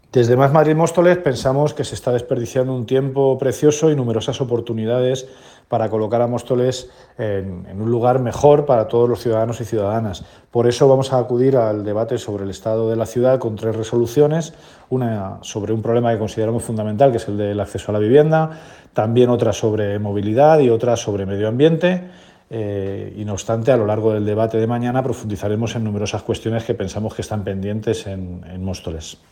Emilio Delgado. Declaraciones estado del municipio